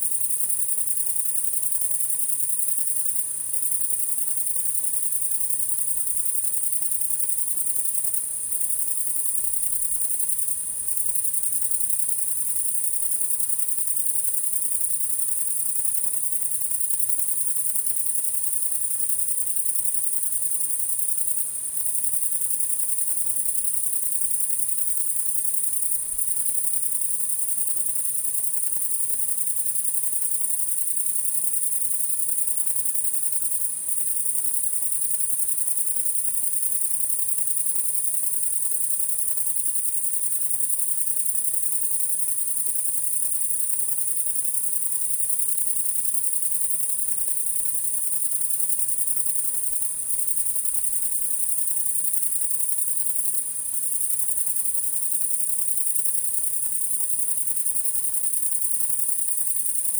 PEPR FORESTT - Monitor PAM - Renecofor CATAENAT sunset+60
Eptesicus serotinus
Nyctalus leisleri